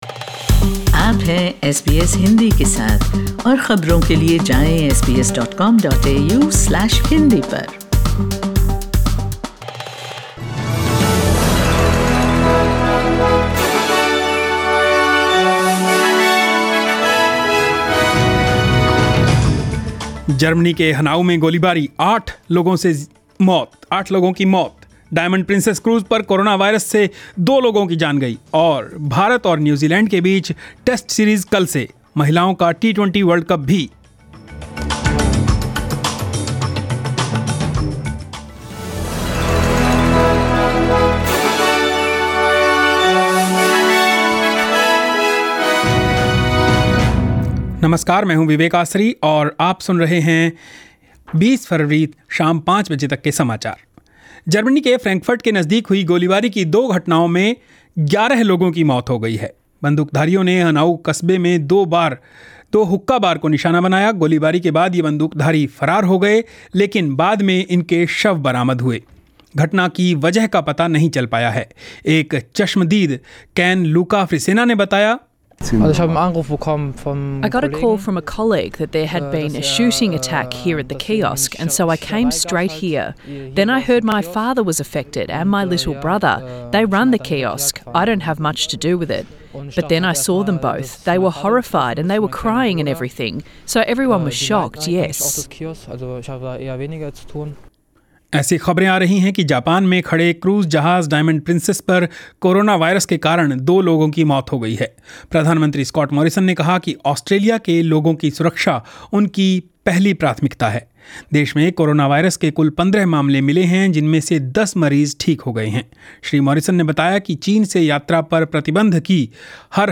News in Hindi 20 February 2020